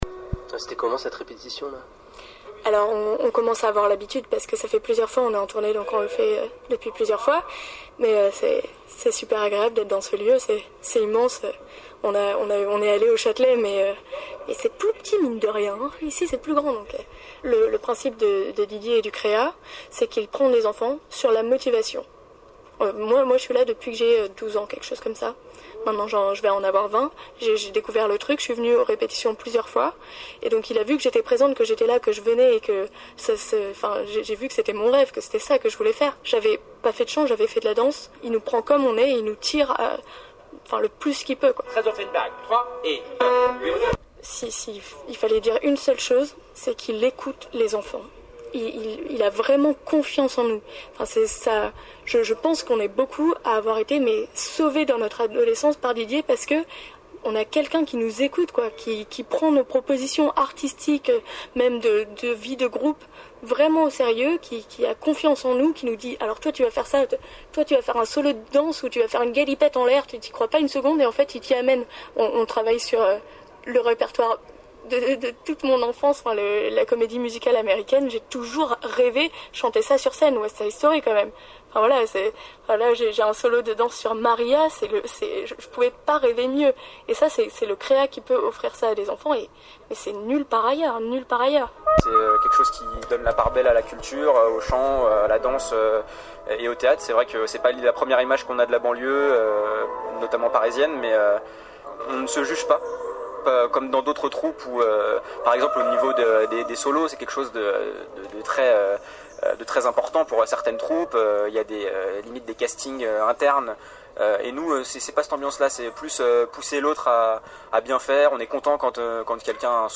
Voici un petit reportage entendu jeudi dernier à la radio, où il est question d’apprendre le sens de l’effort avec patience, d’engagement, de dépassement de soi.